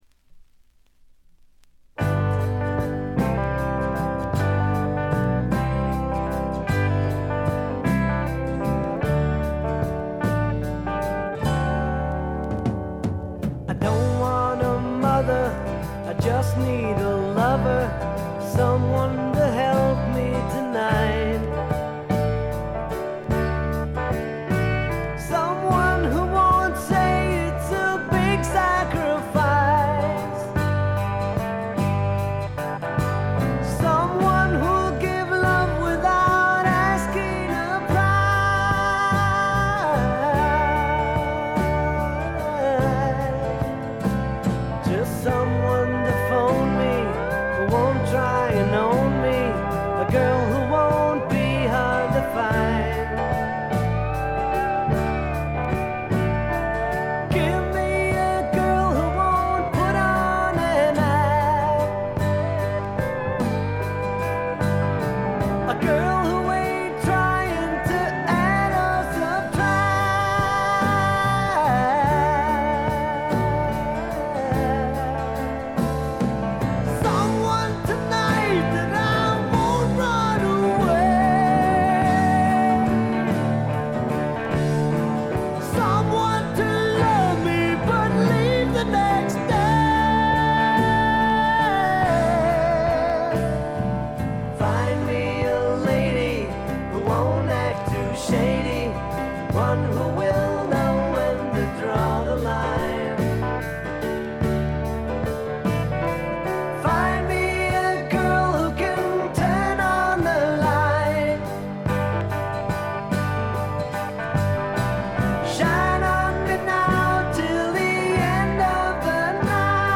軽微なチリプチ少し。
ずばりスワンプ名作！
試聴曲は現品からの取り込み音源です。